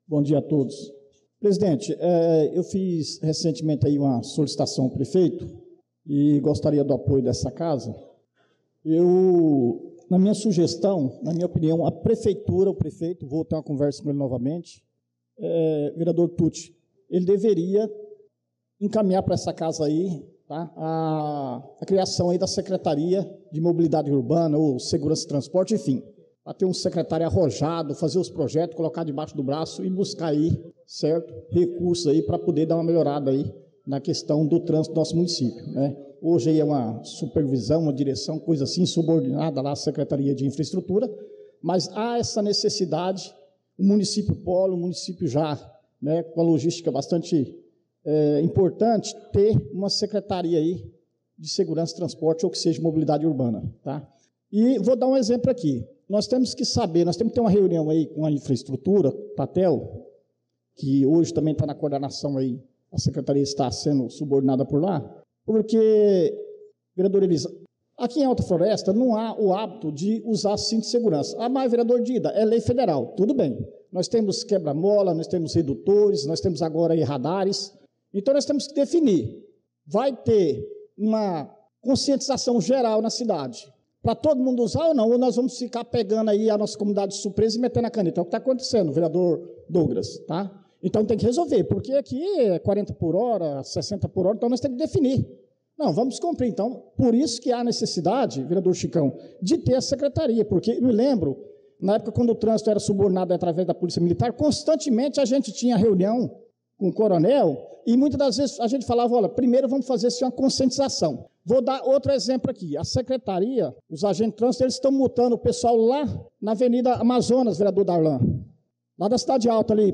Pronunciamento do vereador Dida Pires na Sessão Ordinária do dia 25/02/2025